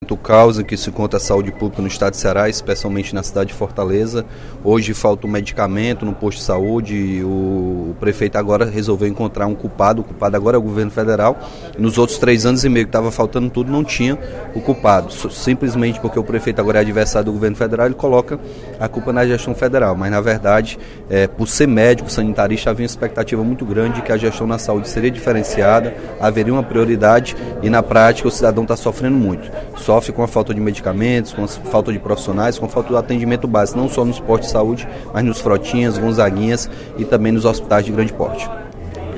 O deputado Capitão Wagner (PR) avaliou, no primeiro expediente da sessão plenária desta quarta-feira (08/06), a gestão da saúde pública em Fortaleza.